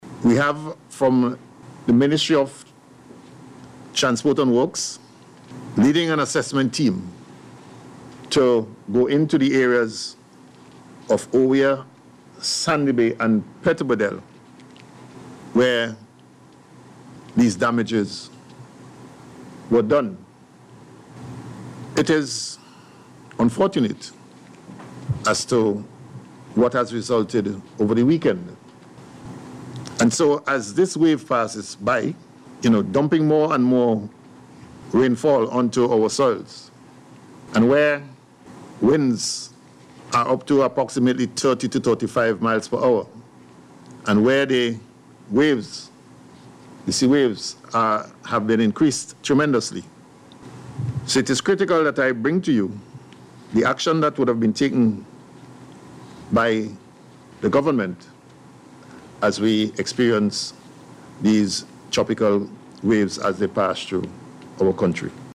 He said at this morning Media Conference that the Ministry of Transport and Works is carrying out an assessment of damage done as a result of the weather system